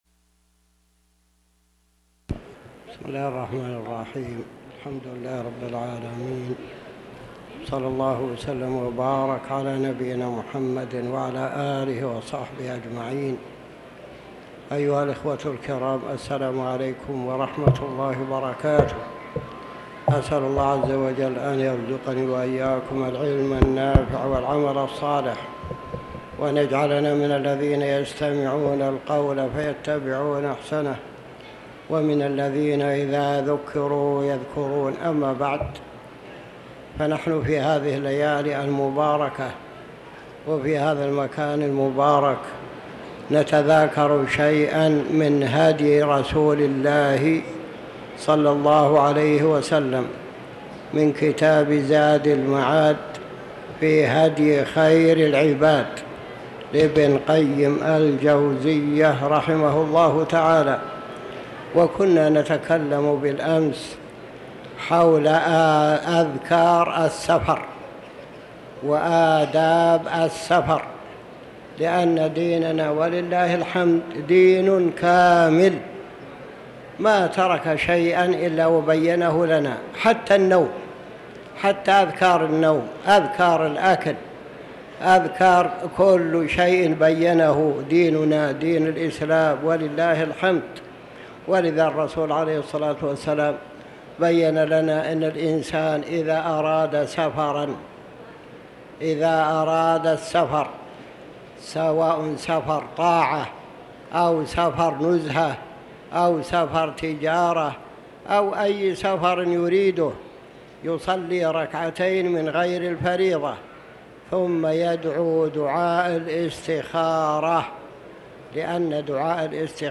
تاريخ النشر ١٨ ذو الحجة ١٤٤٠ هـ المكان: المسجد الحرام الشيخ